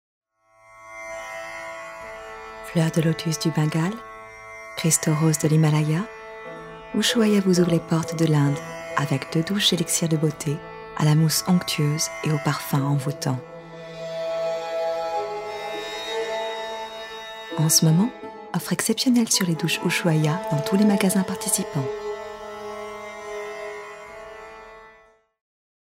Démo voix
- Mezzo-soprano